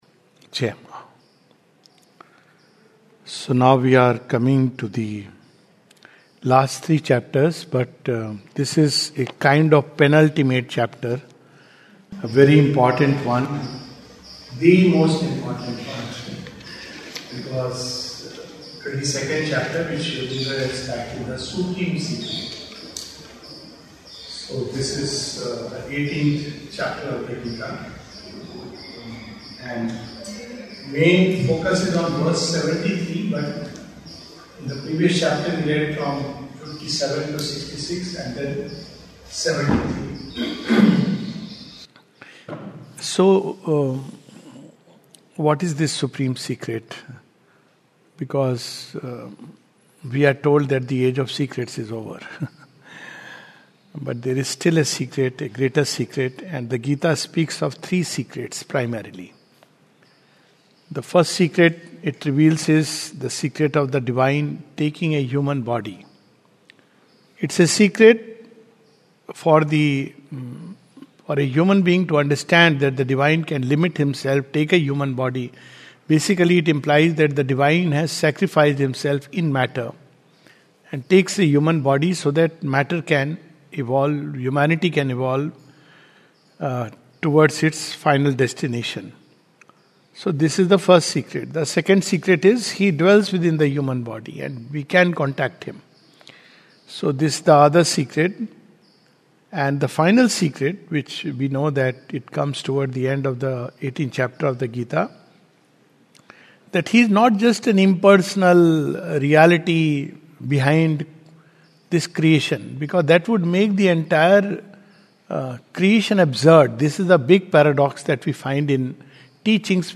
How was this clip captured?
This is the summary of the first part of the 22nd Chapter of the Second Series of "Essays on the Gita" by Sri Aurobindo (p. 540). Recorded on April 1, 2026 at Savitri Bhavan, Auroville.